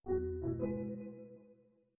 Windows NT 8.0 Beta Startup.wav